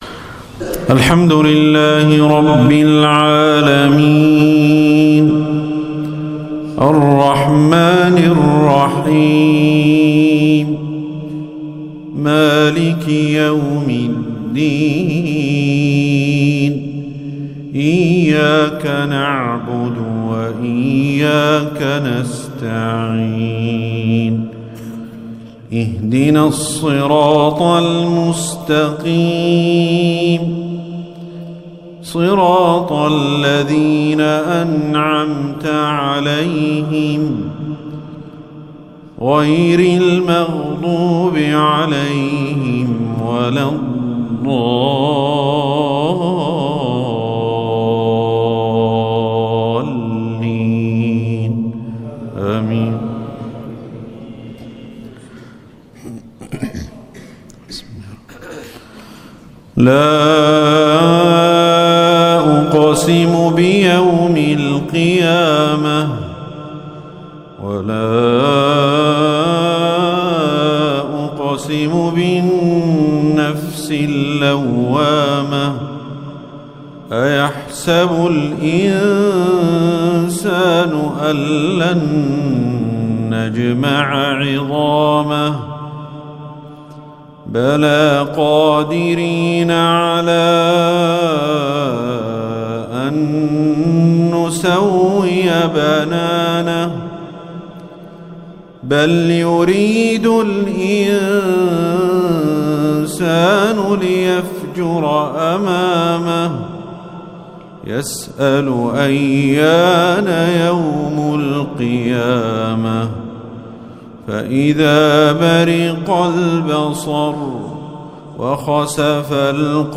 تلاوة خاشعة لسورتي القيامة و الانفطار